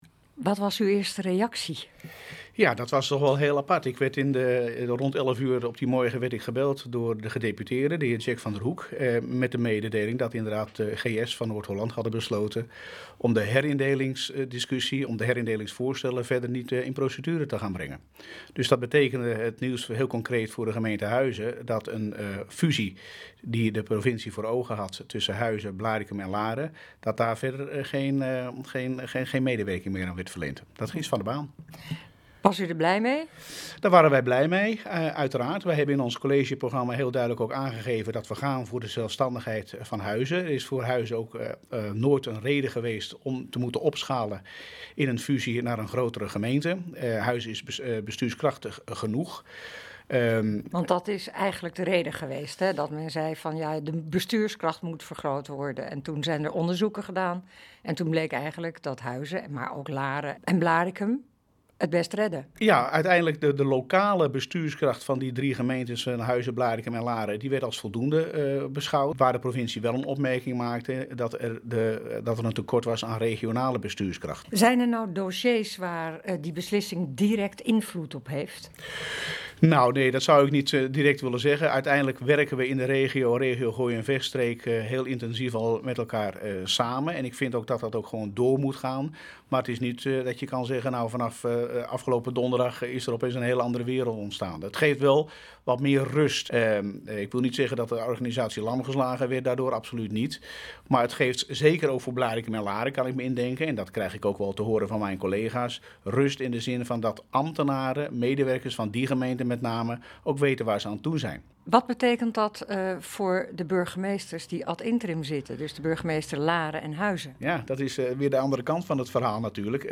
U luistert nu naar NH Gooi Zaterdag - Reactie Wethouder Bert Rebel van Gemeente Huizen over stopzetten AHRI-procedure
nh-gooi-zaterdag-reactie-wethouder-bert-rebel-van-gemeente-huizen-over-stopzetten-ahri-procedure.mp3